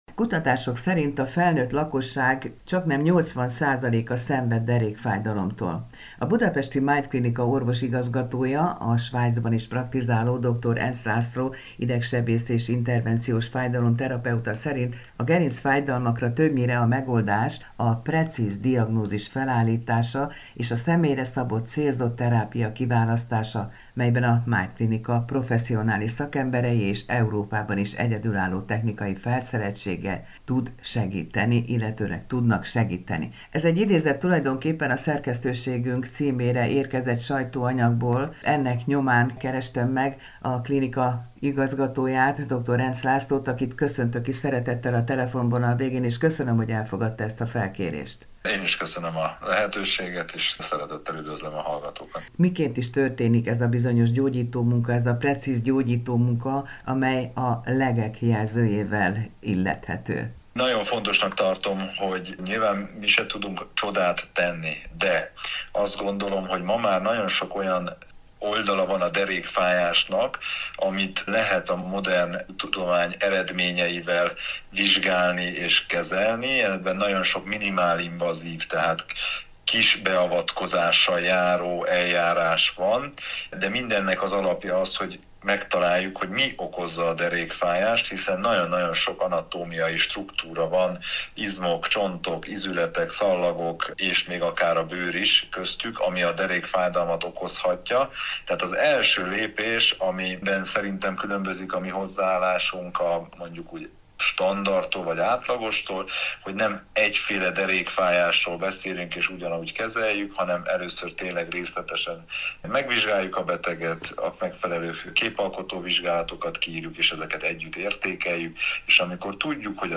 beszélget a népbetegség kezeléséről.